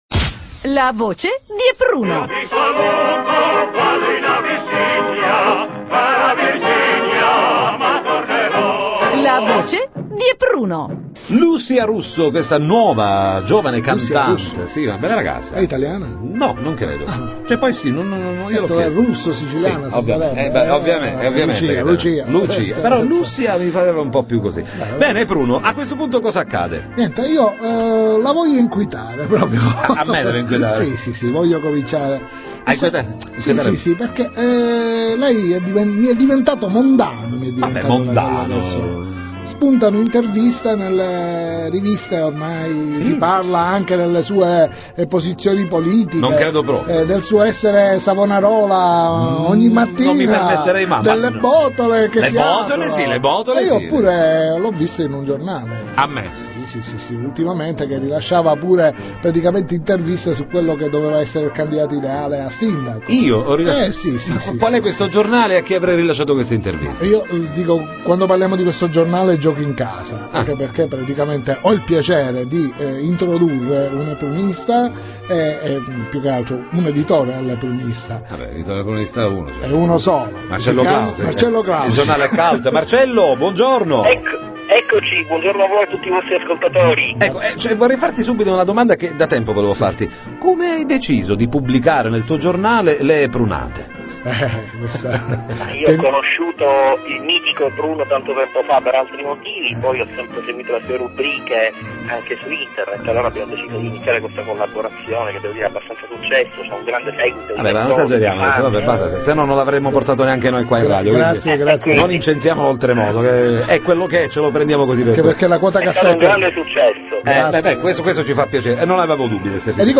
File sonori della puntata in onda: Sabato 28  alle ore 11.00, con repleche: Domenica 29 Gen (ore 9.00 ed ore 20.00); Lunedì 30 Gen (ore 6.00); Mercoledì 1 Feb (ore 23.00) –